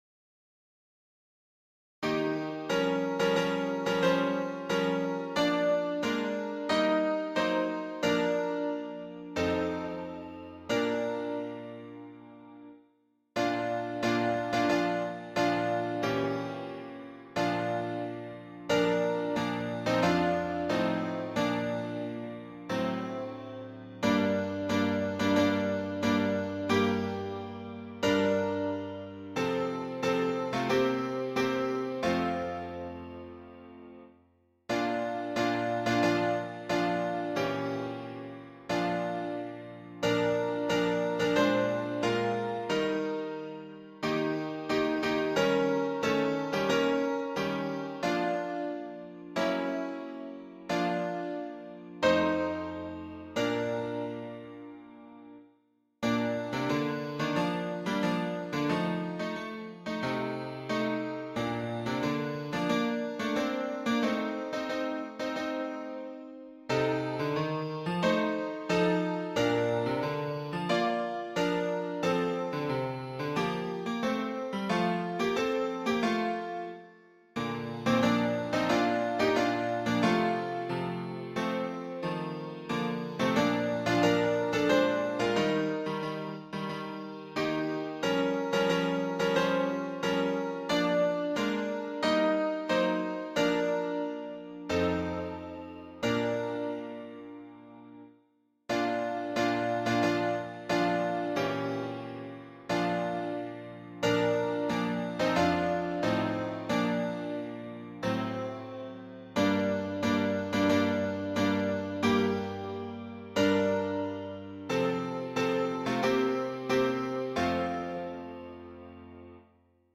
伴奏
示唱